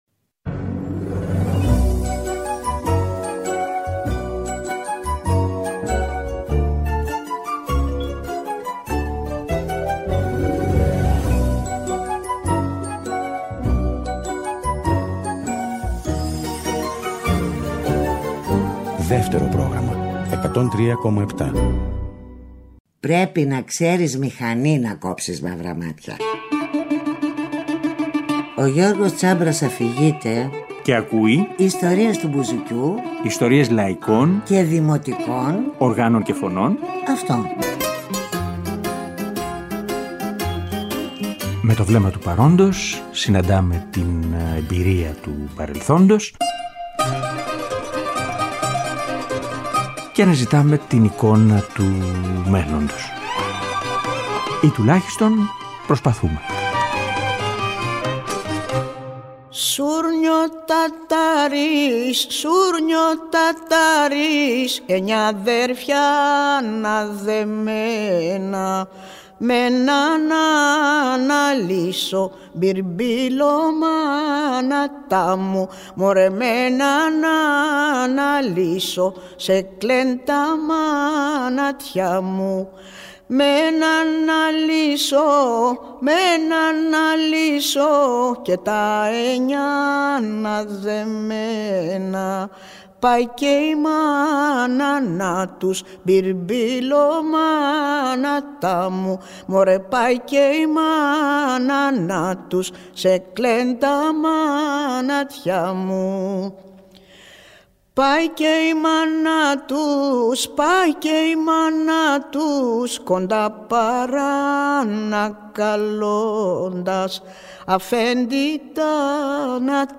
Τραγούδια ιστορικά και τραγούδια …για την ιστορία, από το ’21 κι ως εδώ